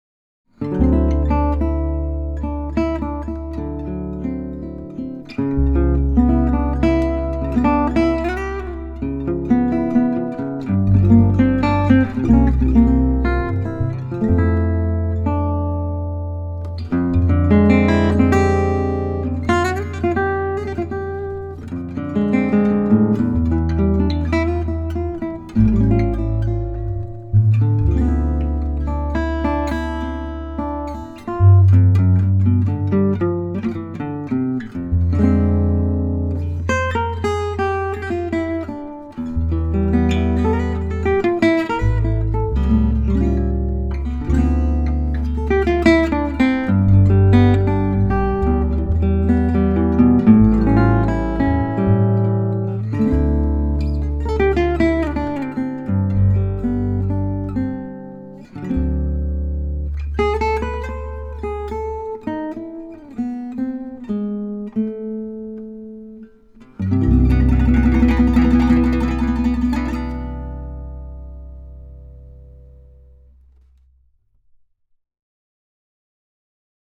Great sounding guitar!
Sounds really nice.
Playing is fantastic and expressive.